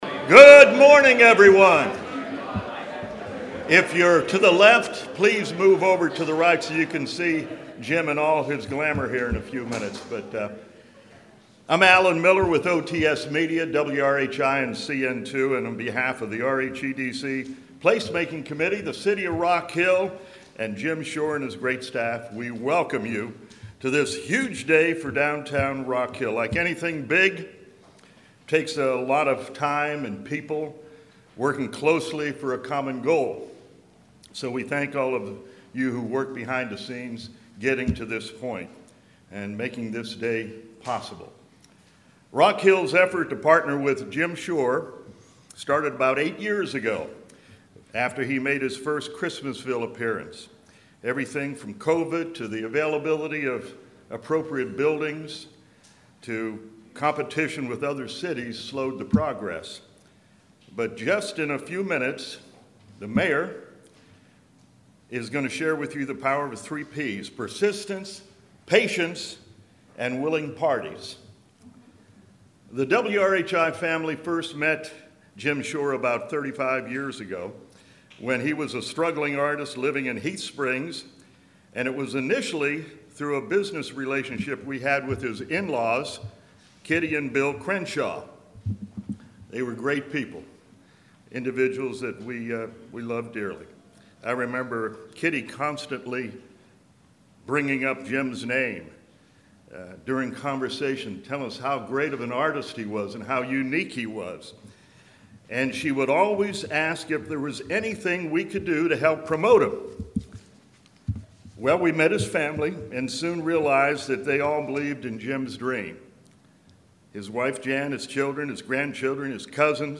Internally renowned artist Jim Shore announced the purchase of an historic building in downtown Rock Hill. Click below to hear the news conference in it’s entirety.